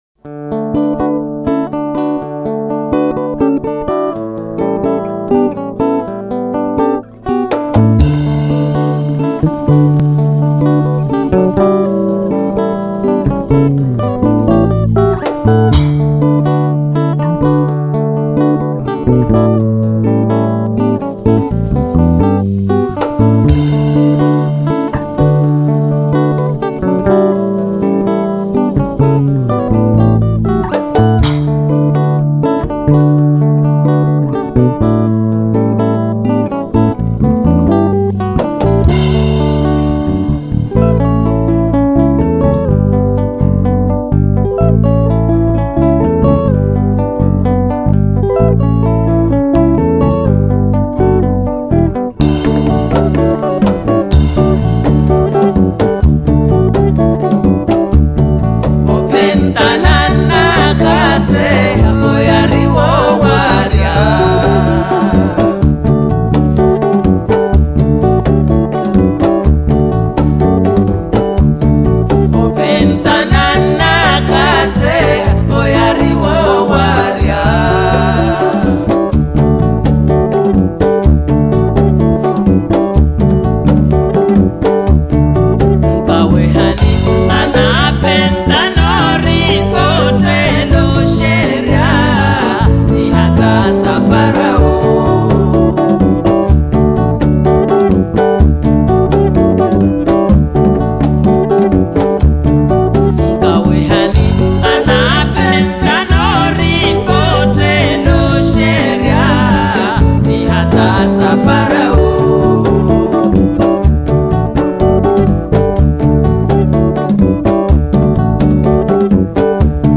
Genero: Traditional Folk